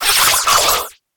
Cri de Poulpaf dans Pokémon HOME.